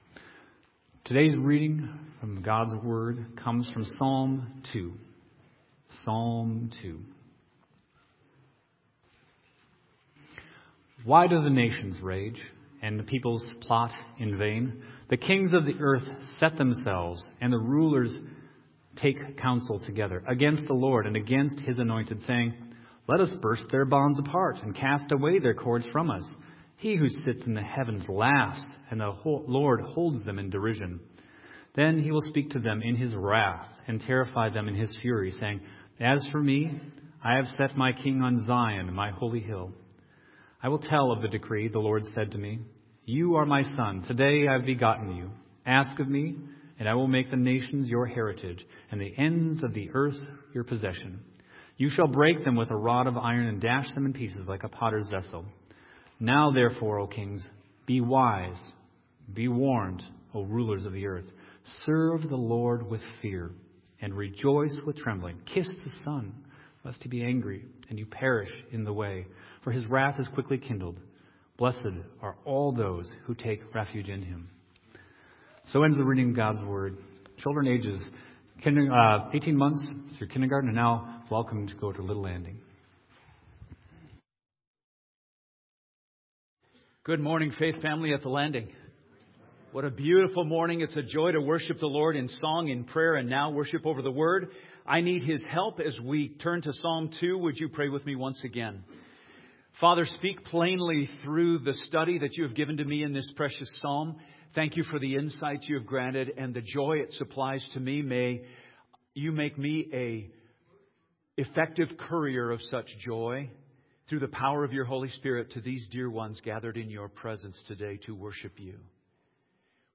Sermon on Missions